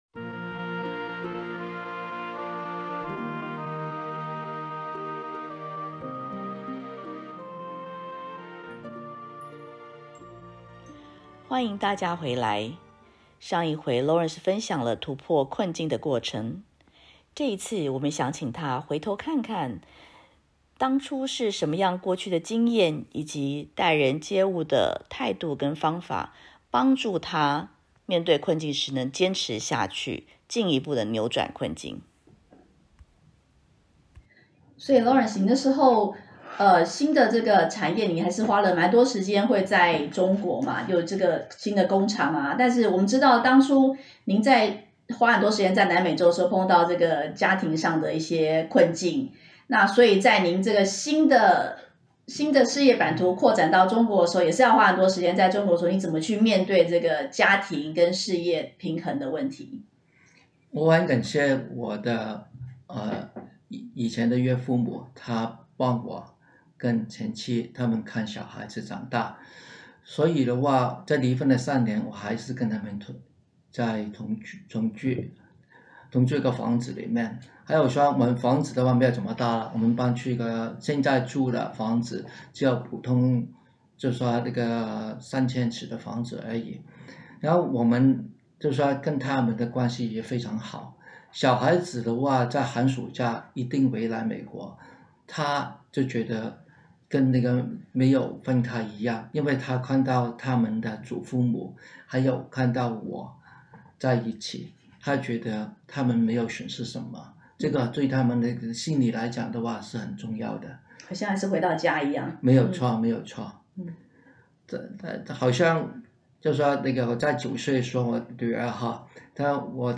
2022 突破逆境系列訪談